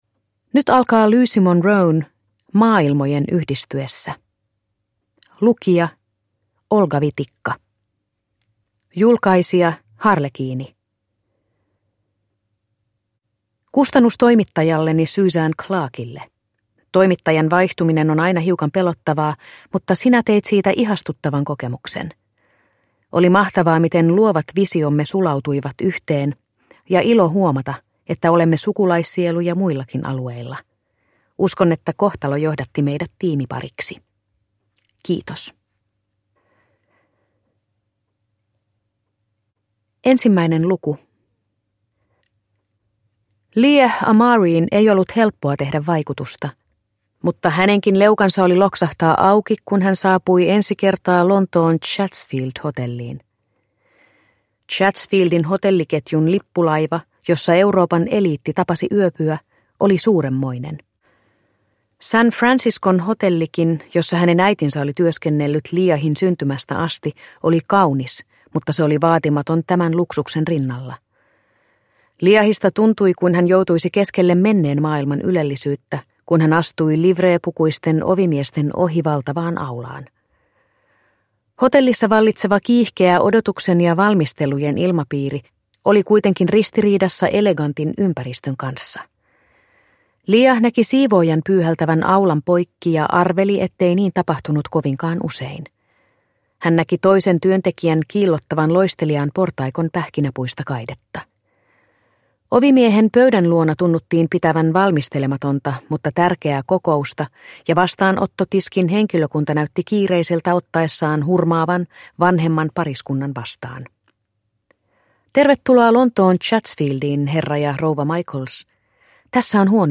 Maailmojen yhdistyessä – Ljudbok – Laddas ner